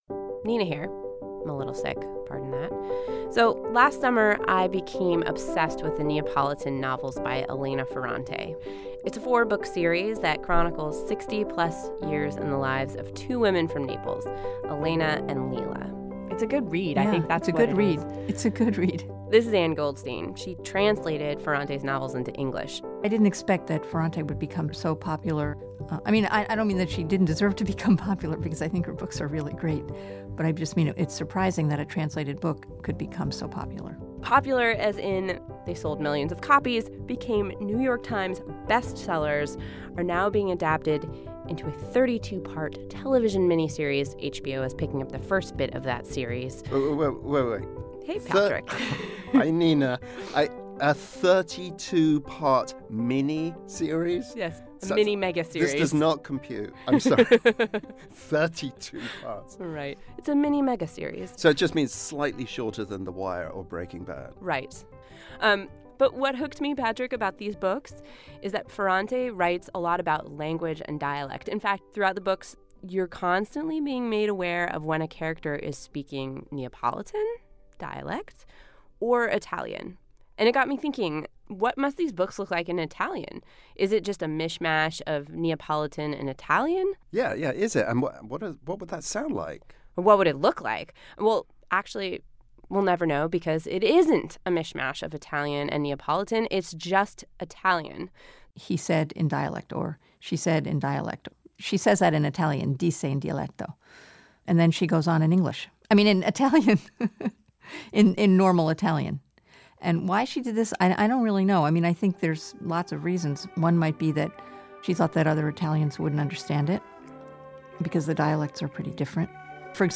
will sing for you in his best Italian accent.